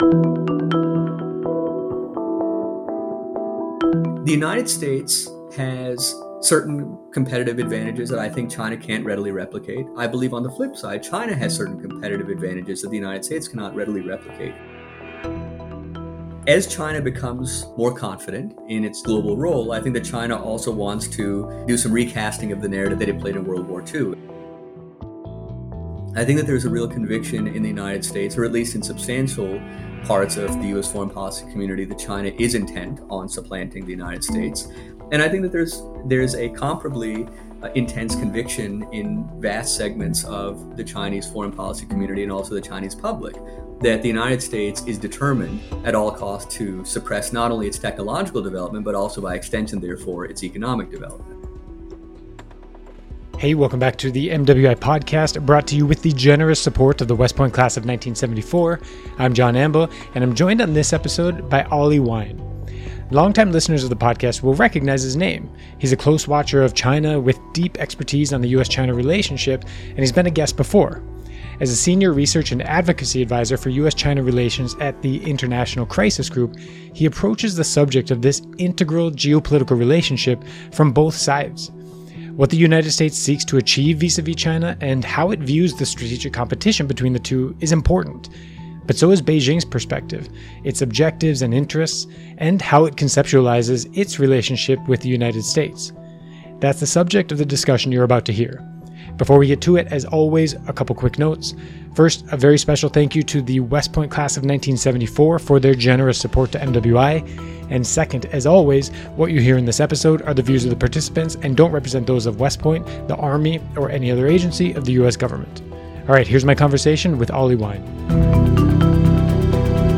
It features discussions with guests including senior military leaders, scholars, and others on the most important issues related to modern military conflict.